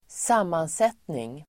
Uttal: [²s'am:anset:ning]